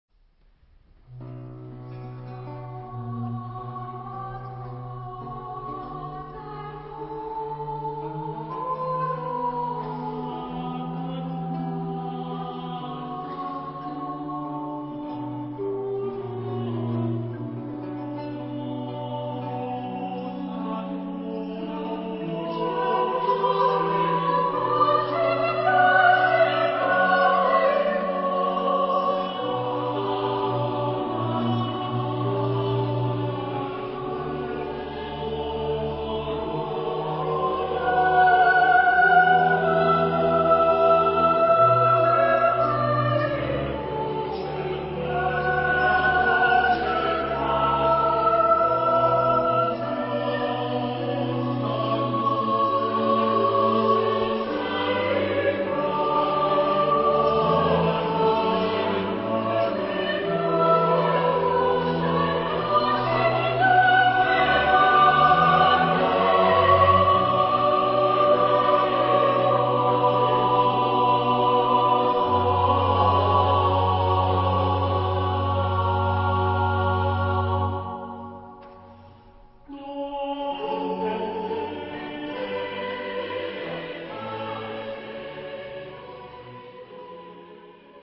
Type de matériel : Choeur et basse continue réalisée
Genre-Style-Forme : Baroque ; Sacré ; Oratorio
Type de choeur : SSSSAATTBB  (10 voix mixtes )
Instruments : Basse continue
Tonalité : do mineur
Réf. discographique : Aria voce en concert - 5ème anniversaire - MRO11